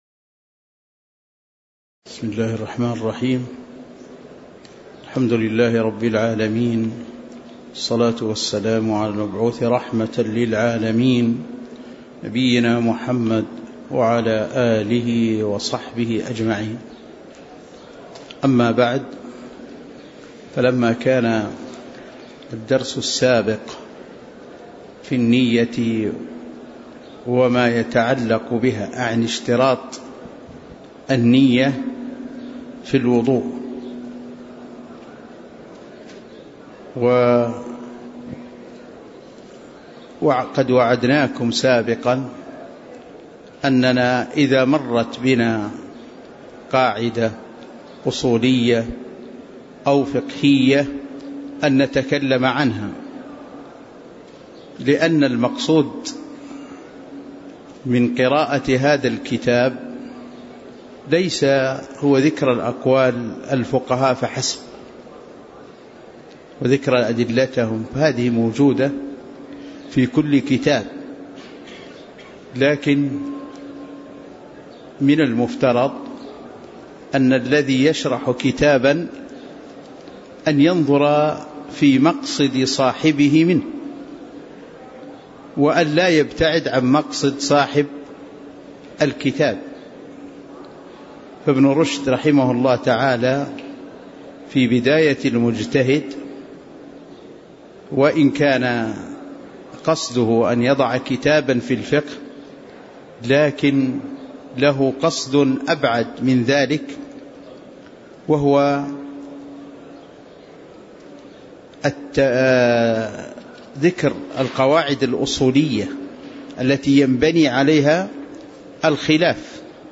تاريخ النشر ٢٤ جمادى الأولى ١٤٣٩ هـ المكان: المسجد النبوي الشيخ